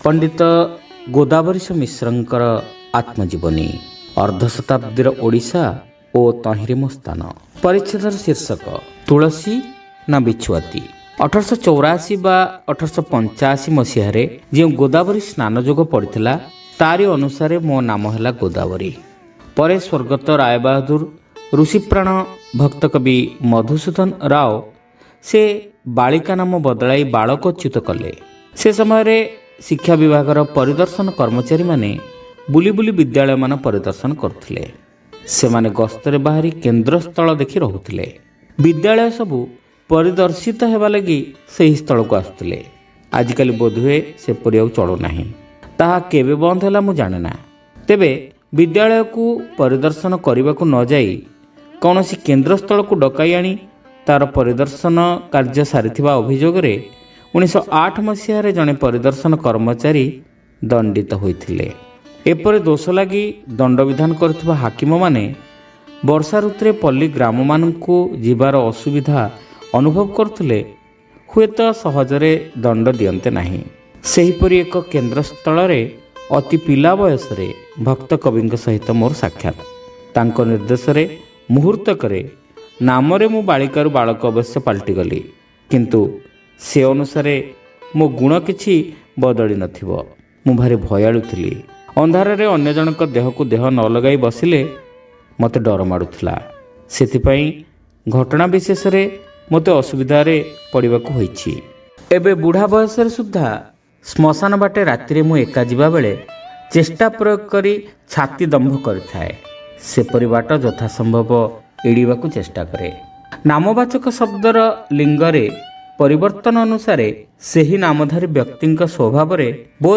Audio Story : Tulasi Na Bichhuati